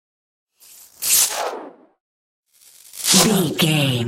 Whoosh sci fi disappear fast
Sound Effects
Fast
futuristic
whoosh